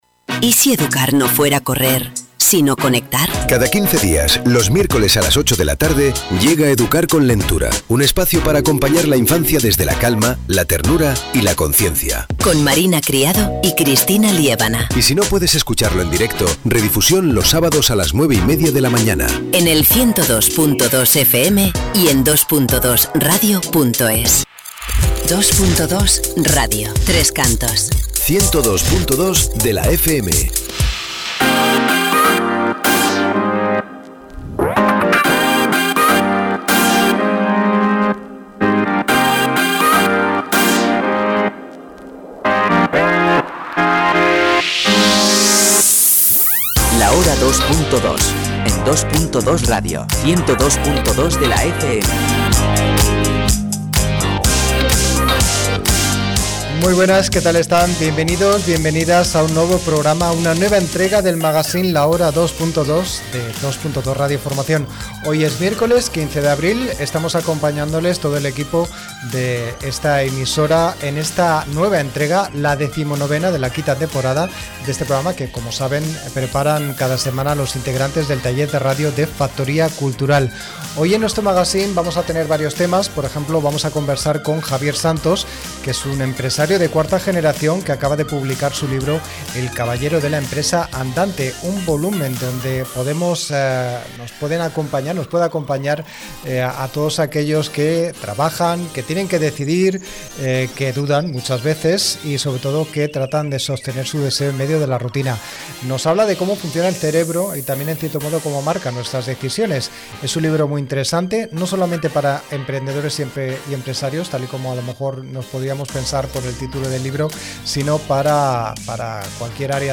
Bienvenidos a una nueva entrega del magazine La Hora 2.2 de Dos.Dos Radio Formación. Hoy es miércoles 15 de abril de 2026 y estamos en una entrega, decimonovena de la quinta temporada de este programa que preparan cada semana los integrantes del taller de radio de Factoría Cultural.